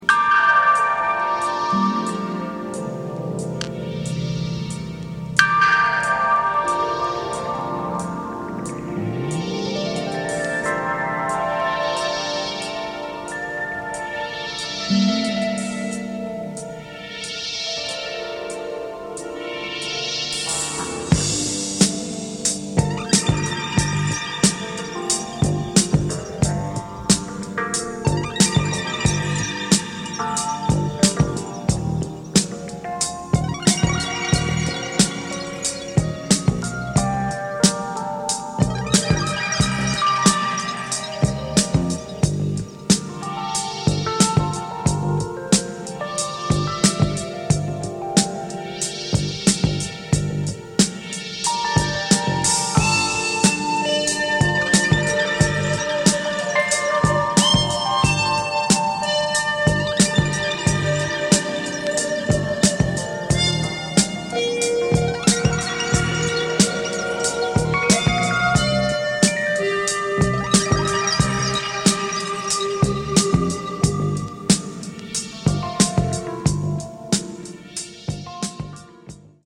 Classic electronic wizadry from the man!